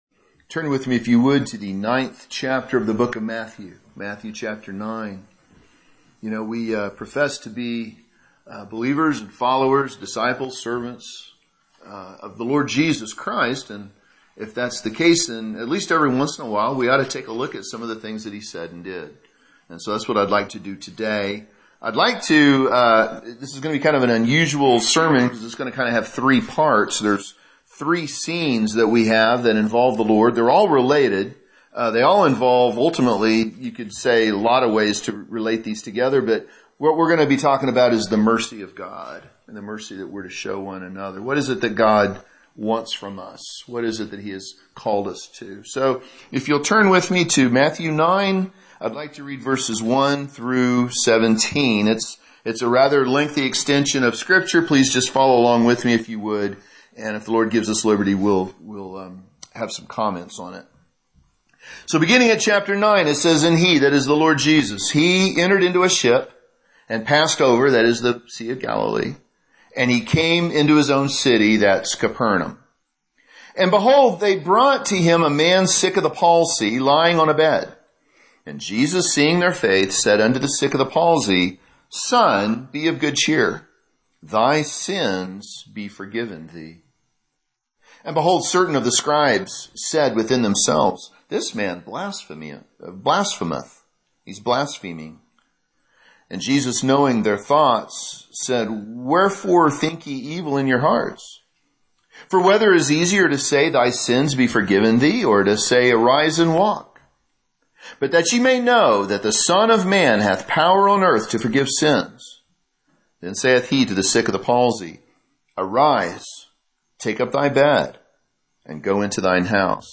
Sermons preached in other churches • Page 11